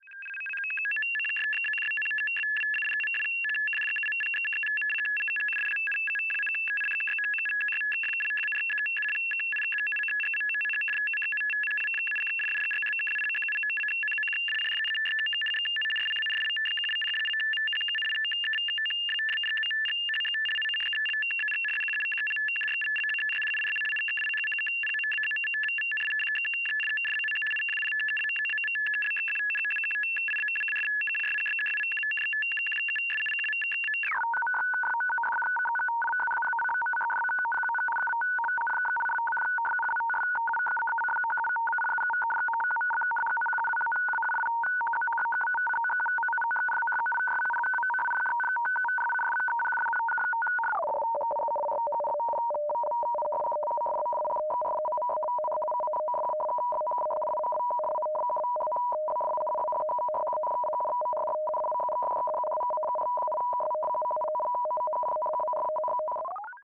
Morse Code: Synthesized, Multiple Speeds And Effects. Mono